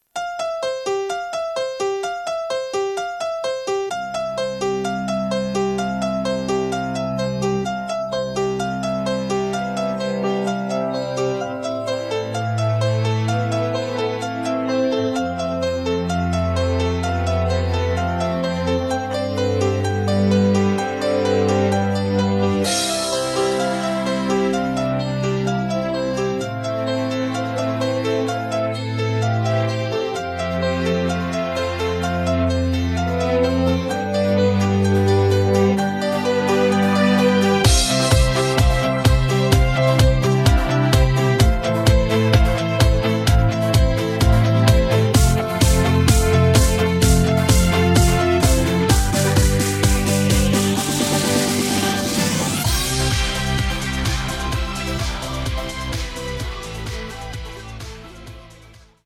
음정 -1키 4:40
장르 가요 구분 Voice MR
보이스 MR은 가이드 보컬이 포함되어 있어 유용합니다.